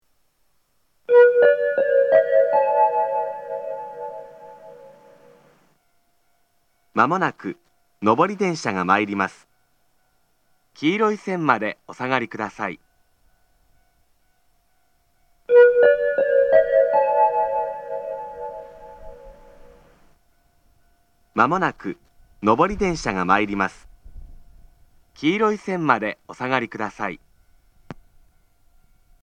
自動放送
仙石旧型（男性）
接近放送
仙石旧型男性の接近放送です。同じ内容を2度繰り返します。